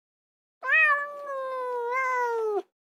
Sound Effects
Cat Meow 15 FX